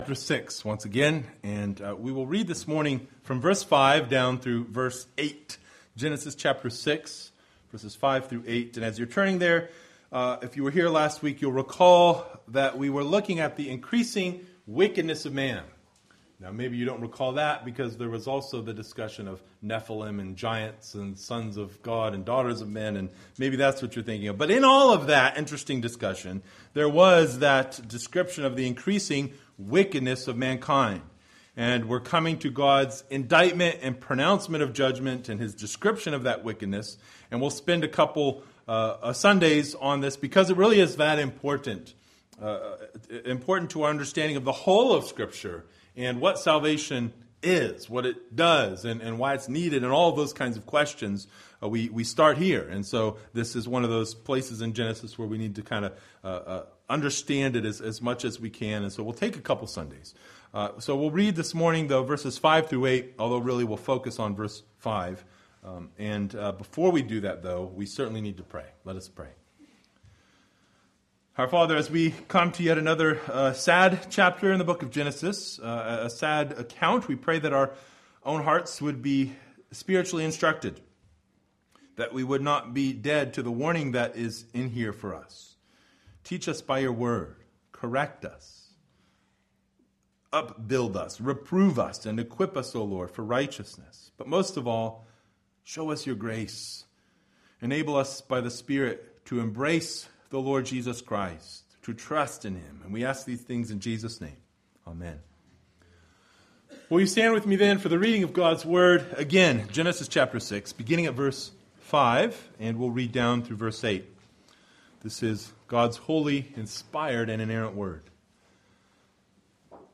Passage: Genesis 6:5-8 Service Type: Sunday Morning